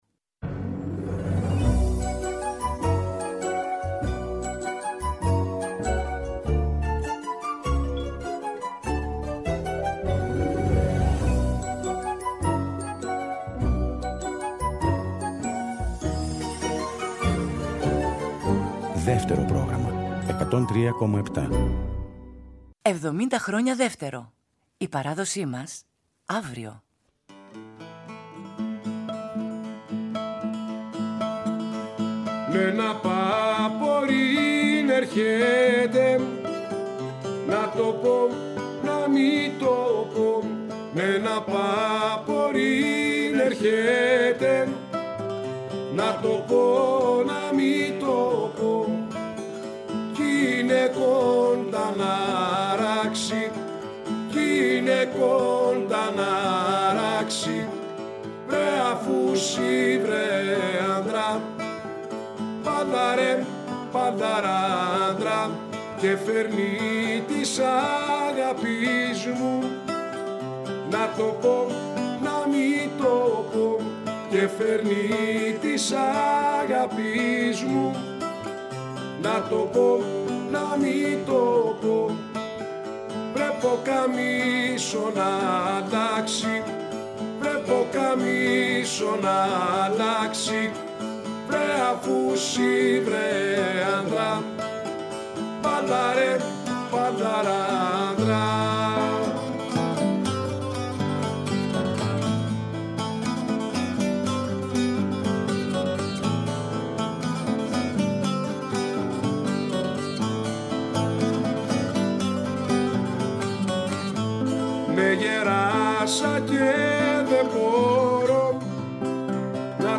με ζωντανές ηχογραφήσεις σε Αθήνα και Θεσσαλονίκη
με κασιώτικα τραγούδια
λαουτο- φωνή
κιθαρα- φωνή
Συναυλία, τραγούδι, γλέντι, χορός.
Κύριο ενδιαφέρον τους αποτελεί η πρωτότυπη ενορχήστρωση, έτσι ώστε να αλλάζουν ρόλους τα όργανά και να προκύπτουν με αυτό τον τρόπο ενδιαφέρουσες ακουστικές συνδιαλλαγές.